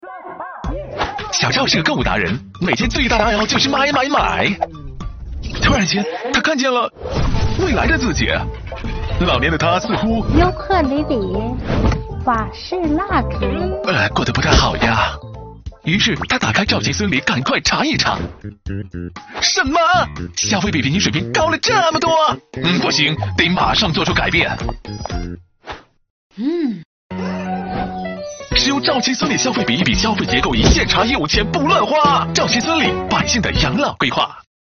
【专题】飞碟说风格 活力.mp3